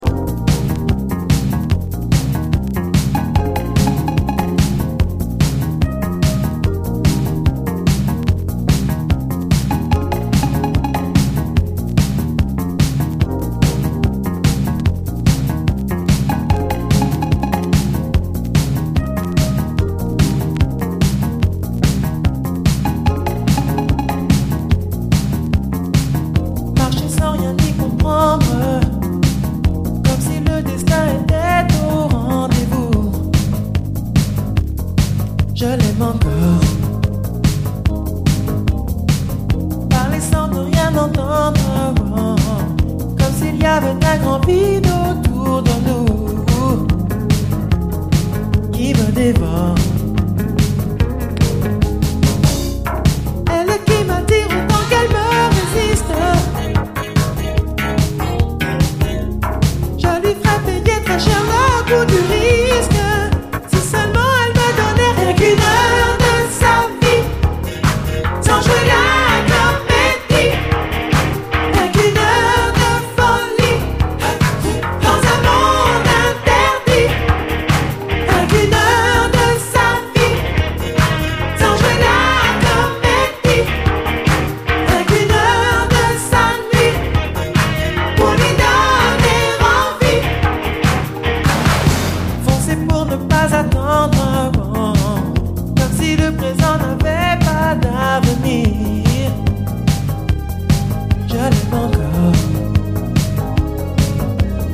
中盤のブレイク以降のピアノも美しいので試聴ファイルは長めです。